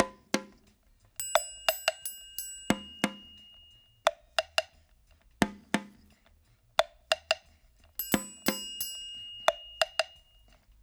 88-PERC1.wav